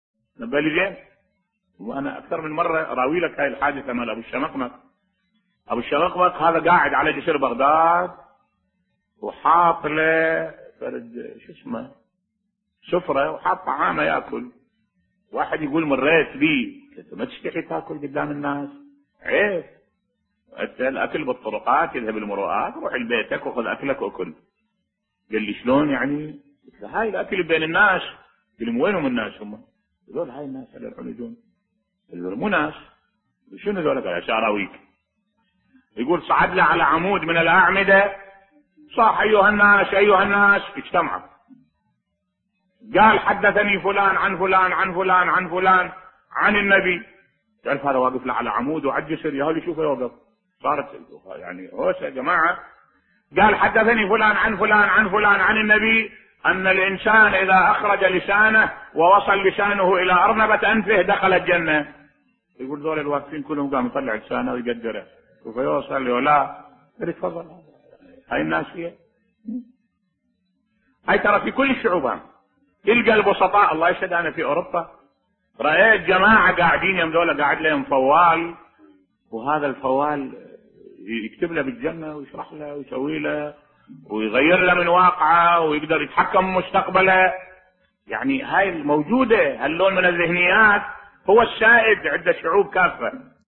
ملف صوتی قصة أبي الشمقمق على جسر بغداد بصوت الشيخ الدكتور أحمد الوائلي